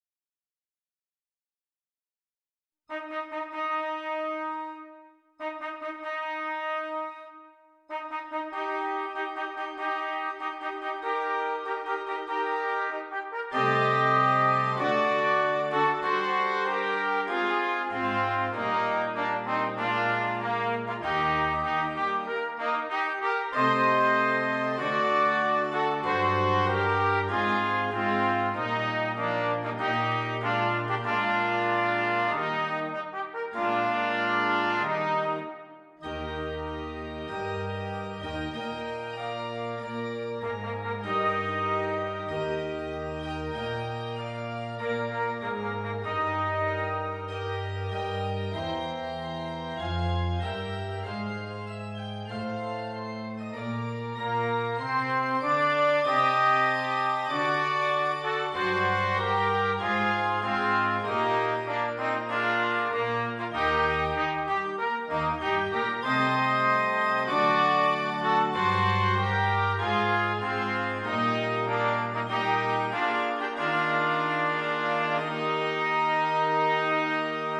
2 Trumpets and Keyboard